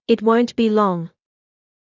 ｲｯﾄ ｳｨﾙ ｼﾞｬｽﾄ ﾃｲｸ ｱ ﾐﾆｯﾂ